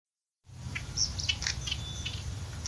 Ticking Doradito (Pseudocolopteryx citreola)
vimos 3 ejemplares , todos vocalizaron .
Life Stage: Adult
Location or protected area: San José del Rincón
Condition: Wild
Certainty: Photographed, Recorded vocal